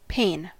Ääntäminen
IPA : /peɪn/